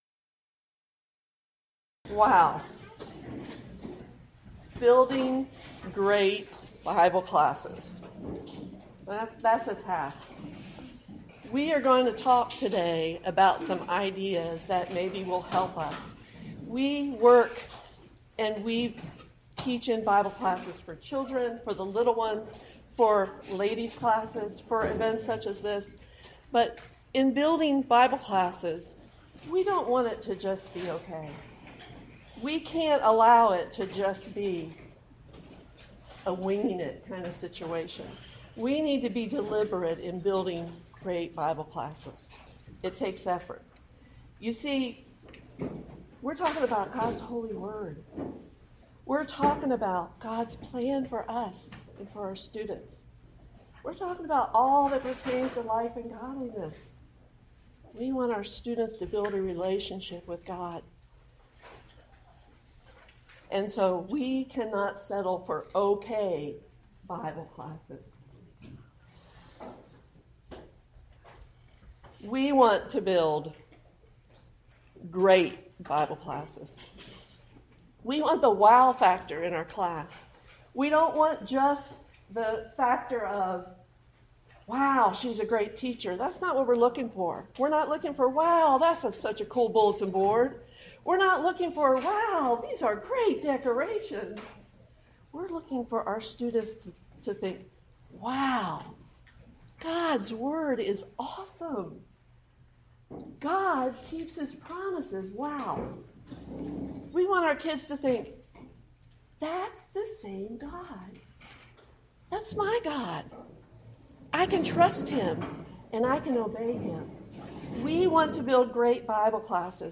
Event: 4th Annual Arise Workshop
lecture